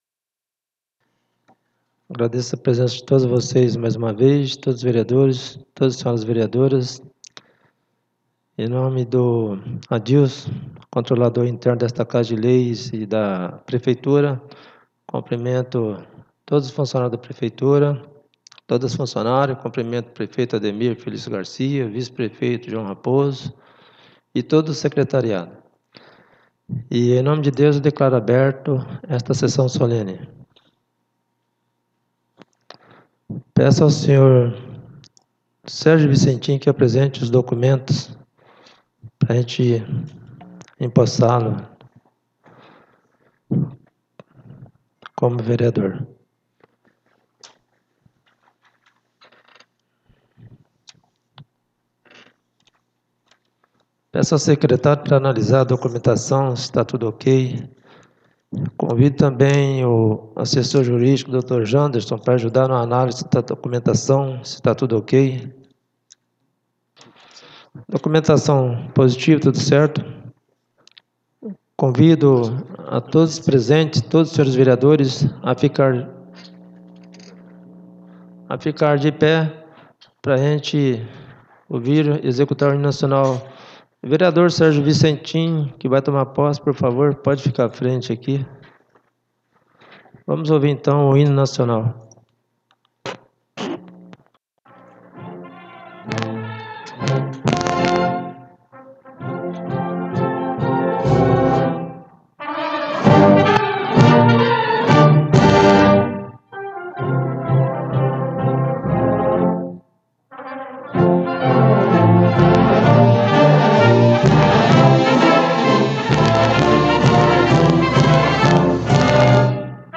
2° SESSÃO SOLENE DE POSSE DIA 25 DE SETEMBRO DE 2025